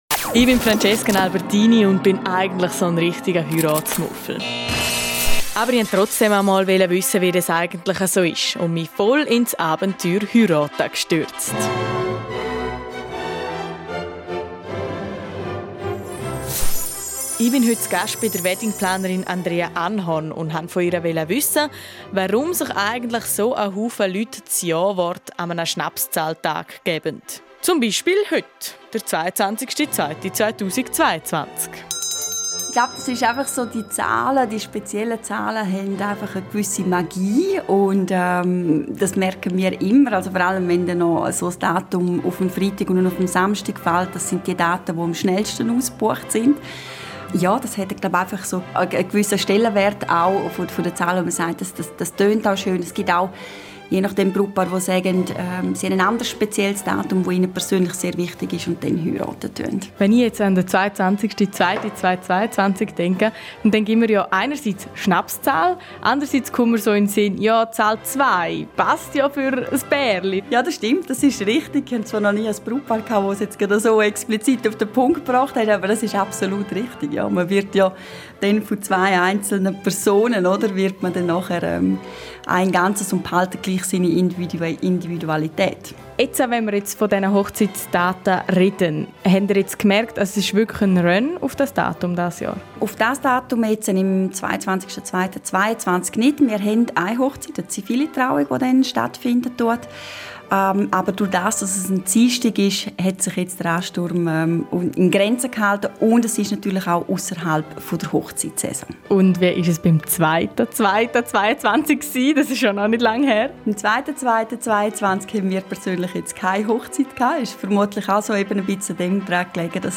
Dementsprechend neigen so einige Paare dazu, ihr Hochzeitsdatum auf heute zu terminieren. Radio Südostschweiz im Gespräch mit Weddingplanerin